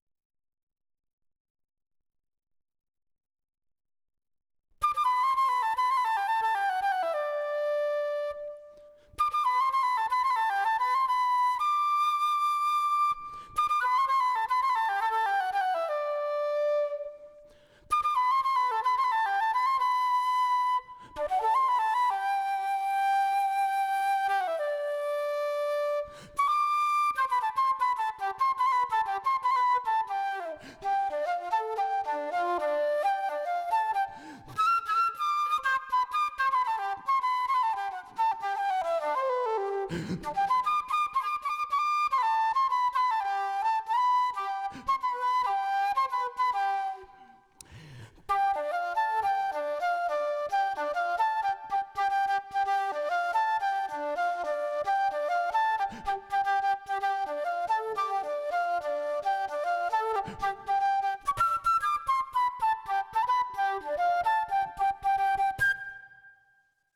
I suggest this in light of the attached test example (which is of a flute) where the pitch range is almost out of the range allowed by Tony. (ex. the last note in the excerpt is completely out of the range).
- flute 11.5 MB
Now I added another octave (but just saw that even that doesn't capture the last flute note (it's > 1600Hz).